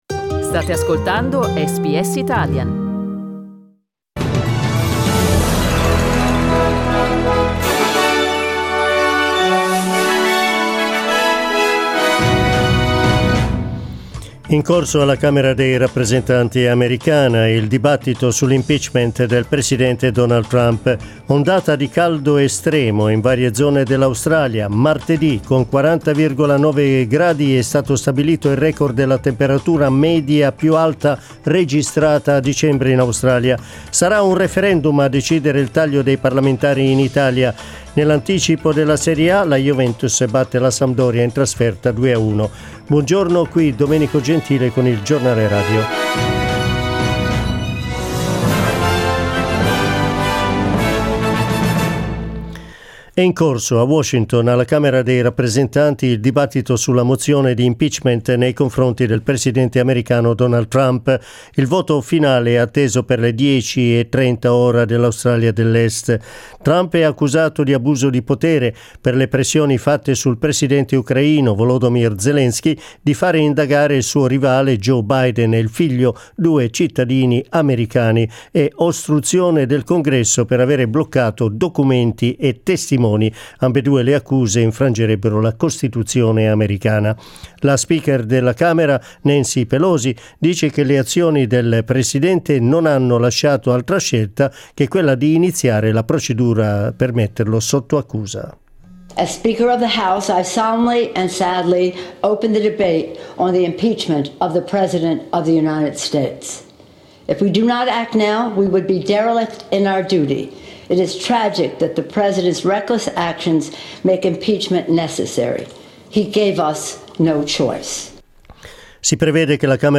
Giornale radio giovedì 19 dicembre